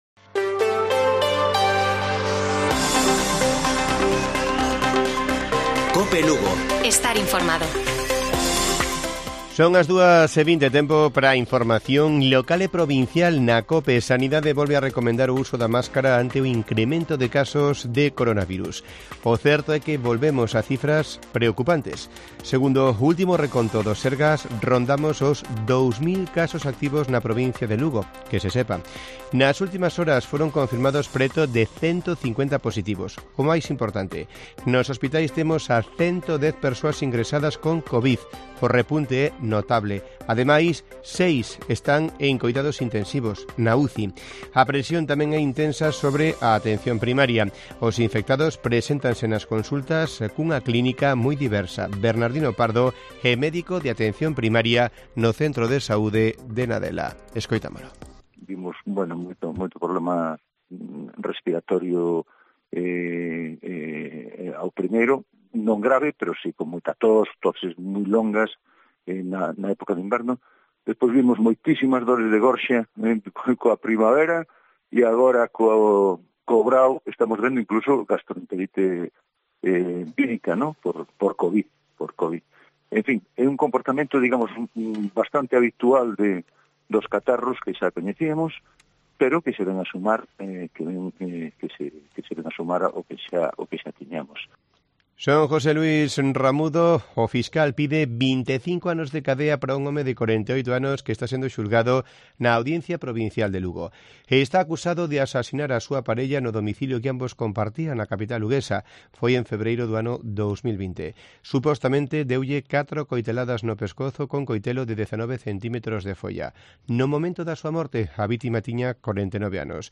Informativo Mediodía de Cope Lugo. 04 DE JULIO. 14:20 horas